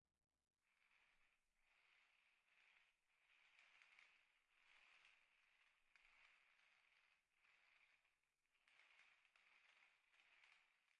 OWI " 雨打窗台
描述：通过将可移动的蔬菜架拖过瓷砖来创建。
标签： 性质 OWI 愚蠢 安静
声道立体声